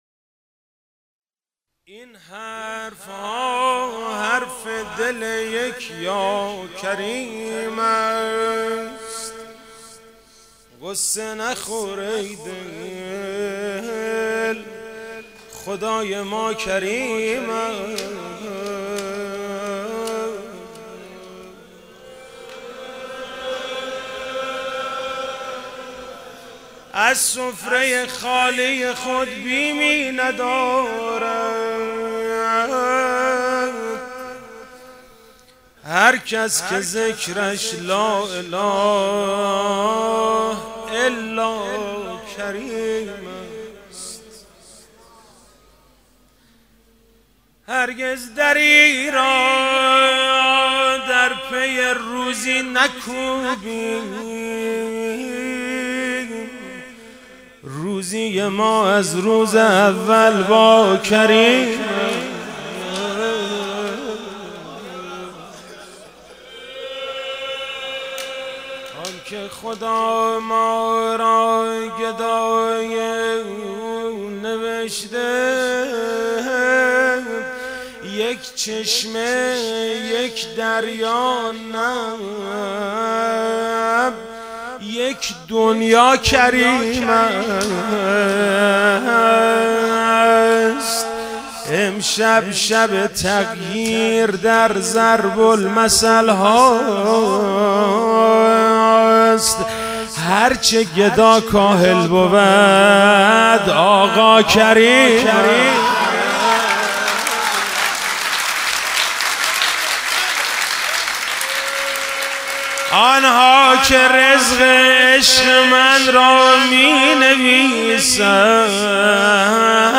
ولادت امام حسن مجتبی (ع)